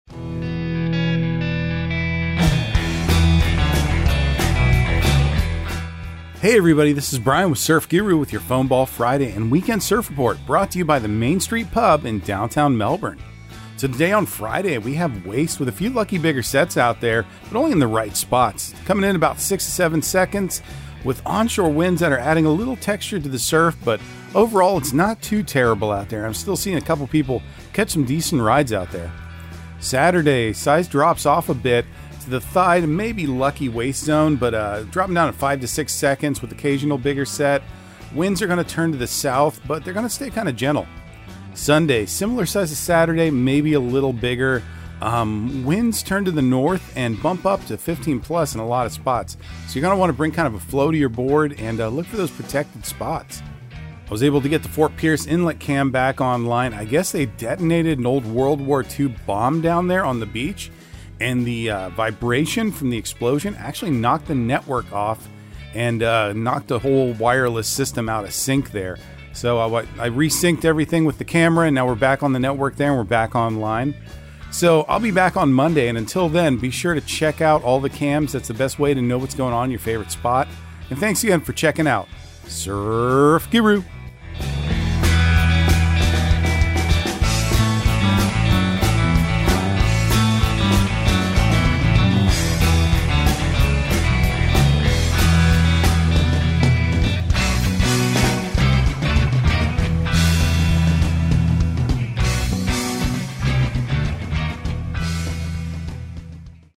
Surf Guru Surf Report and Forecast 04/21/2023 Audio surf report and surf forecast on April 21 for Central Florida and the Southeast.